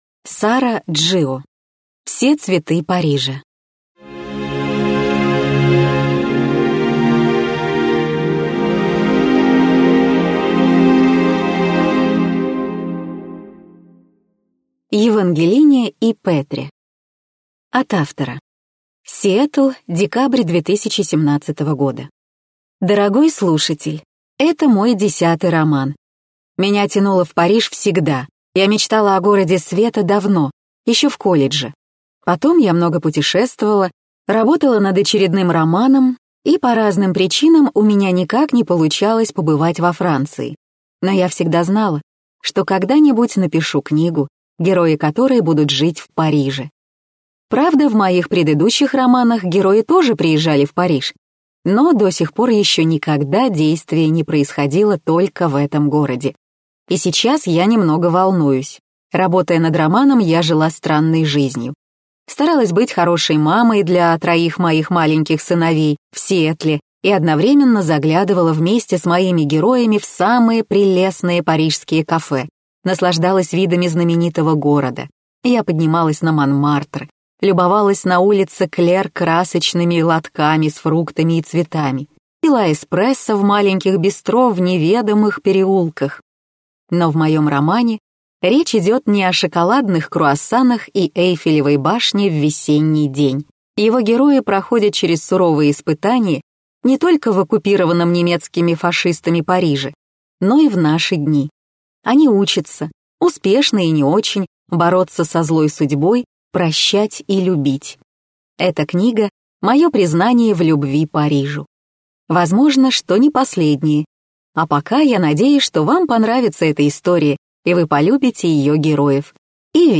Аудиокнига Все цветы Парижа - купить, скачать и слушать онлайн | КнигоПоиск